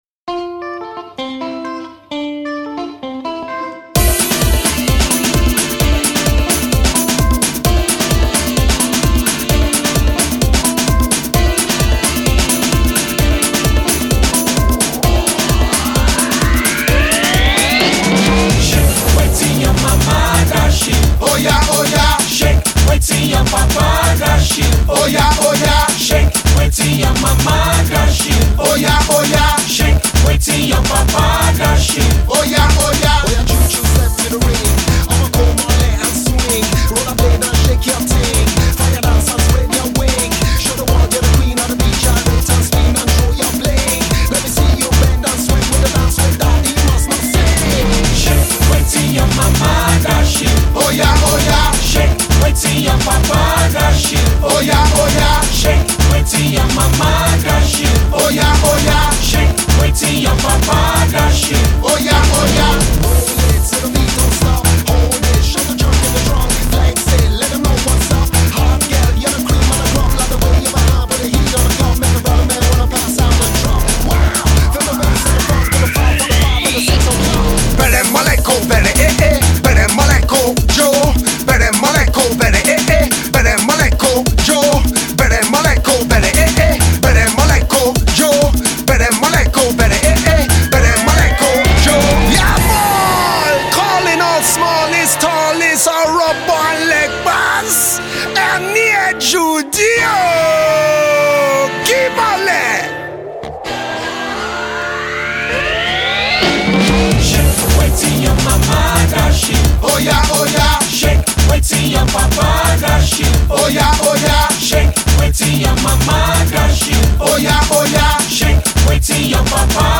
Afro-Funk meets Afro-Hip-Hop meets Afrobeats.
is a feel good dance your heart out tune